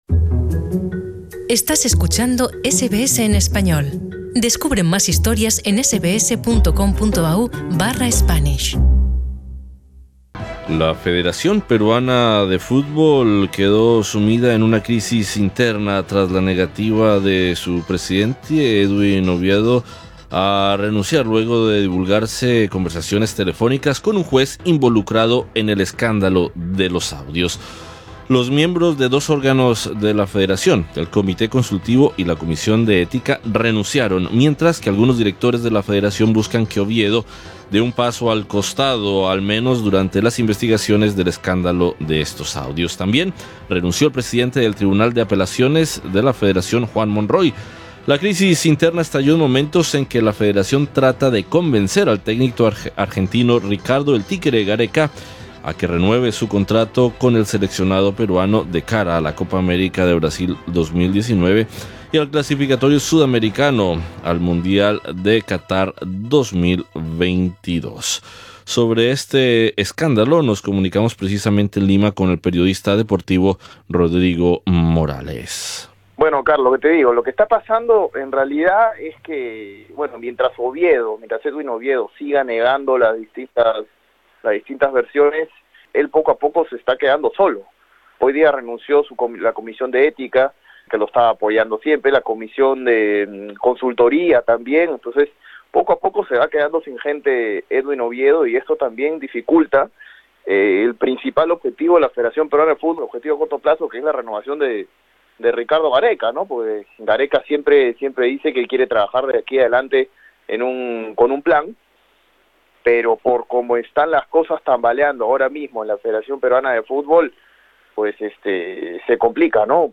Sobre este escándalo, nos comunicamos en Lima con el periodista deportivo